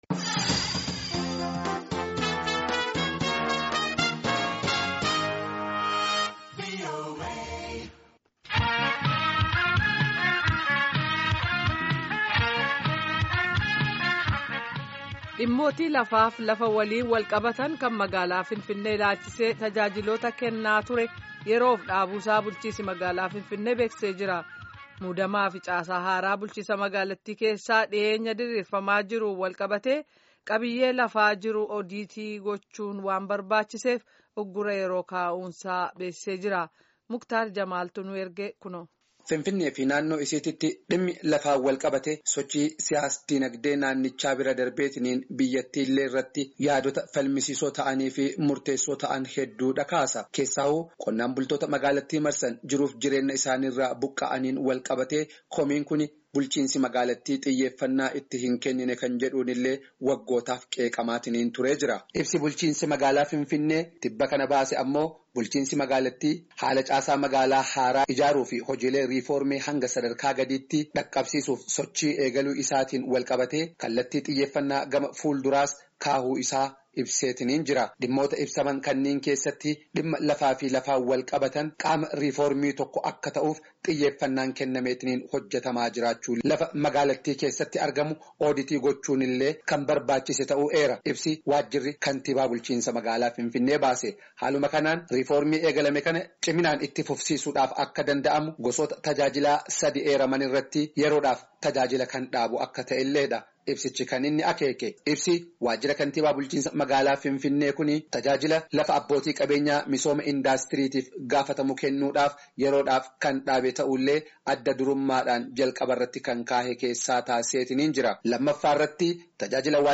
Gabaasa Guutuu